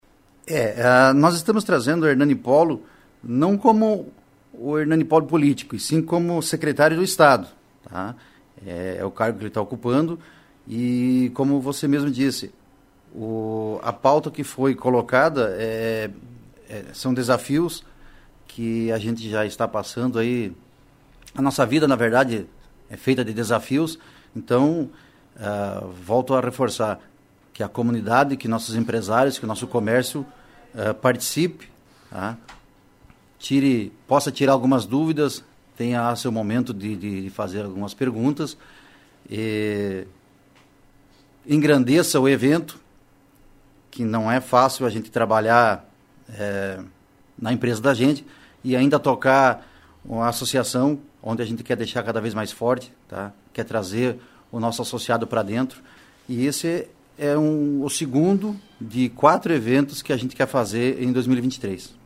Ouça a entrevista: 1307_CF_ACINT 02 1307_JM_ACINT 01 1307_JM_ACINT 02